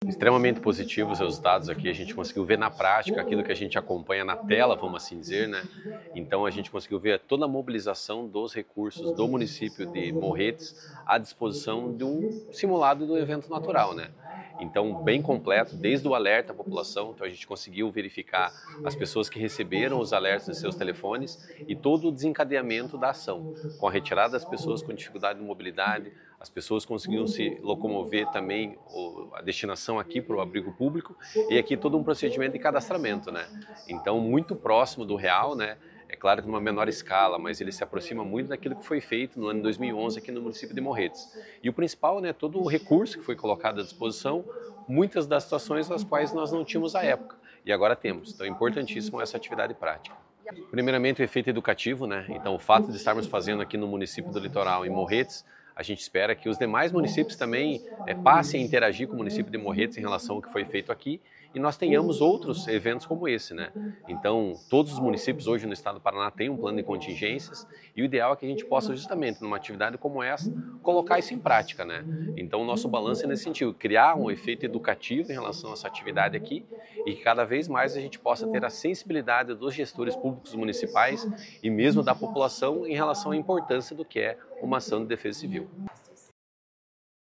Sonora do coordenador executivo da Defesa Civil Estadual, Ivan Fernandes, sobre o simulado para situações de desastre em Morretes